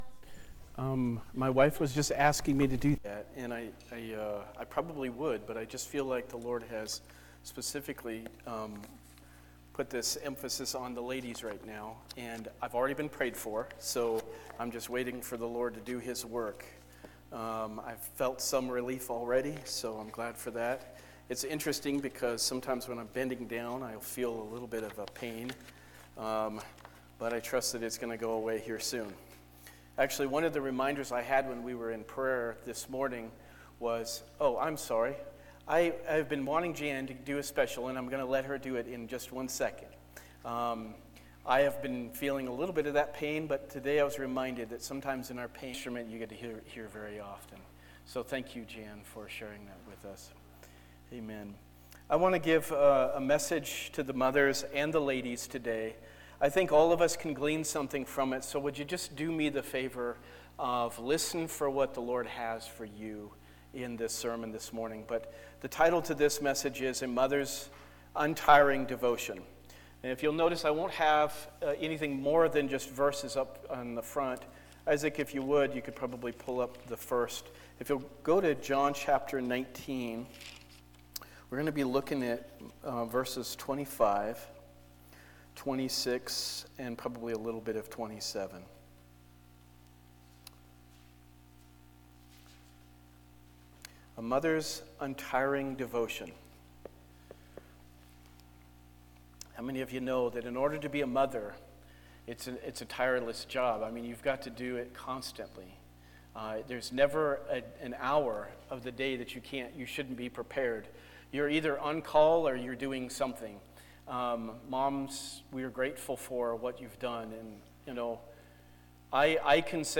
Sermons by Abundant Life Assembly